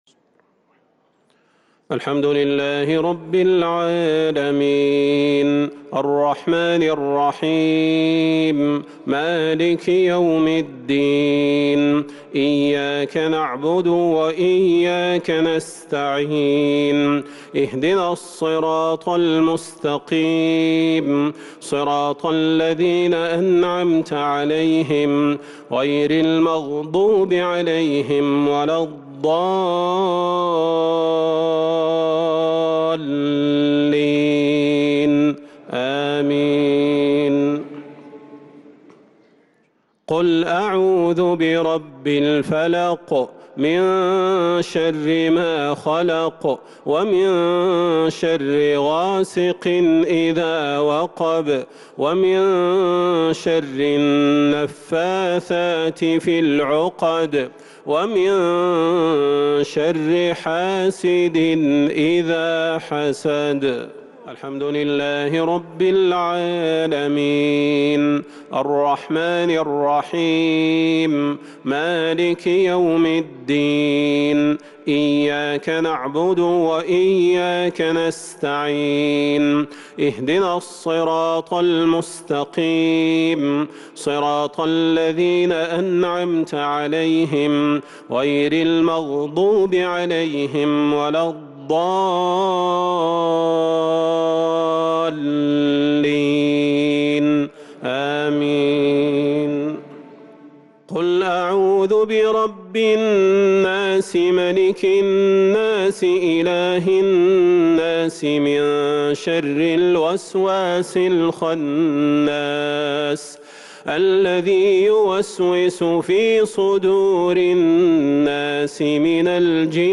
عشاء 3 رمضان 1443هـ  سورتي الفلق و الناس | lsha prayer from Surat Al-Falaq and An-Nas 4-4-2022 > 1443 🕌 > الفروض - تلاوات الحرمين